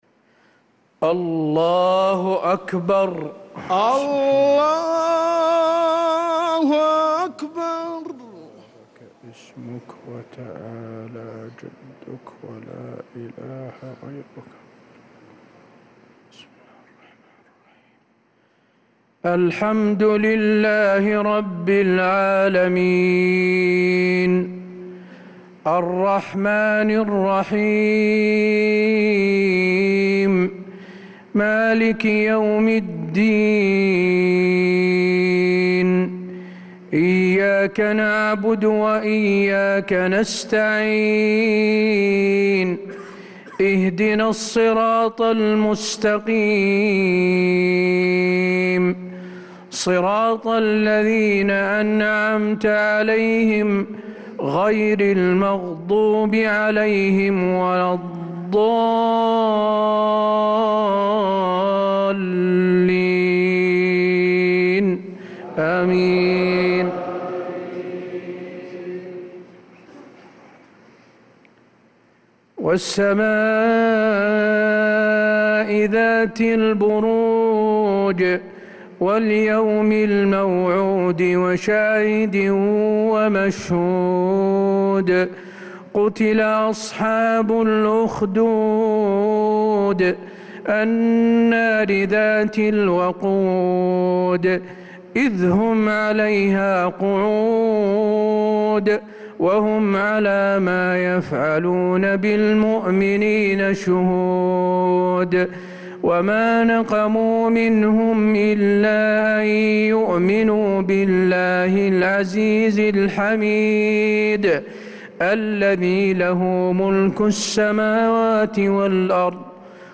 صلاة العشاء للقارئ حسين آل الشيخ 21 ربيع الأول 1446 هـ
تِلَاوَات الْحَرَمَيْن .